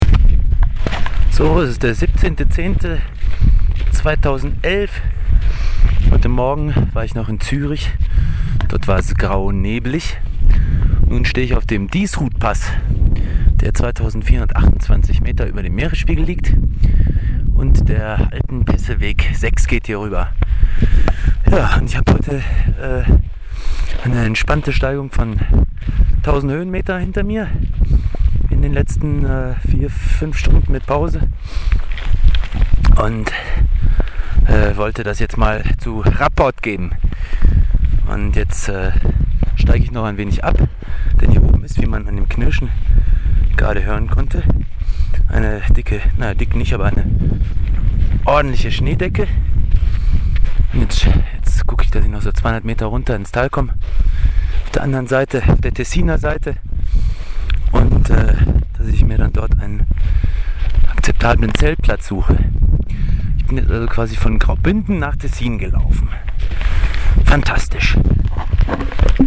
Ein kleiner Audiobericht meiner Alpenpassüberquerung mit Zelt und Krempel.